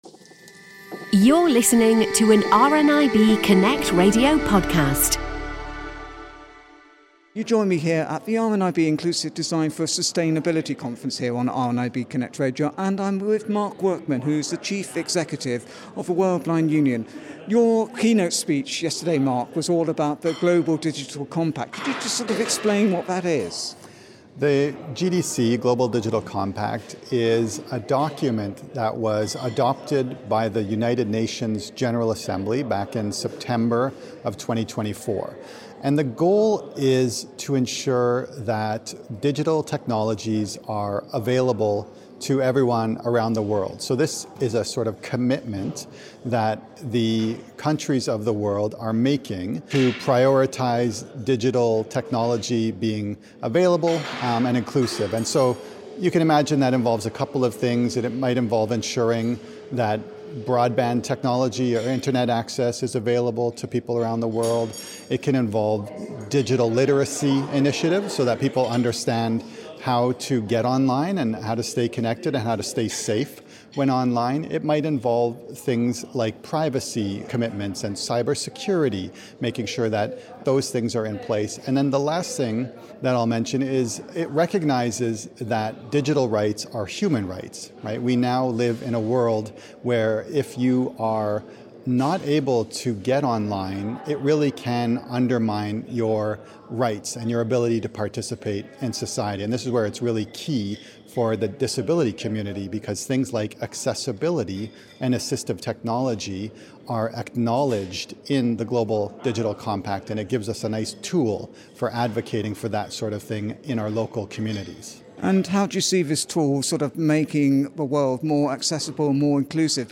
At the RNIB Inclusive Design For Sustainability Conference in Glasgow